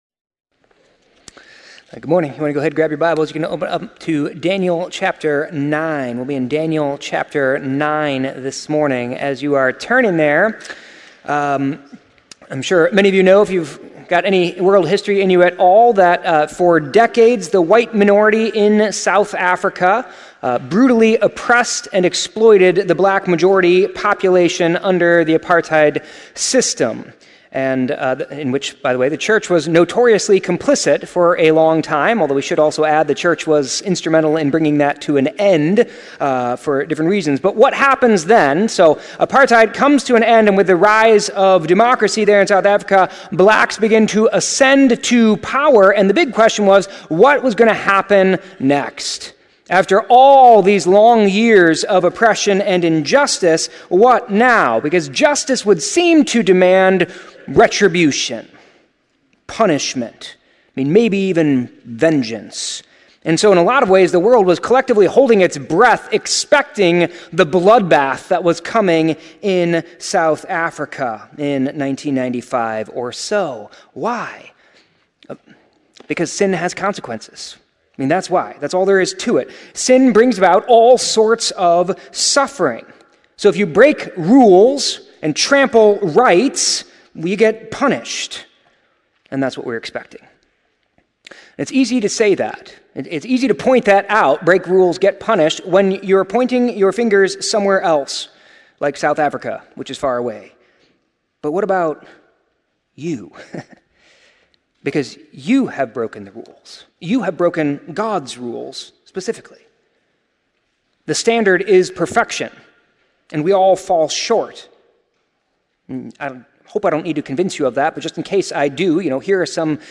The sermon on Daniel 9 discusses the consequences of sin, both individually and corporately, and how Daniel’s prayer of confession and repentance models the proper response.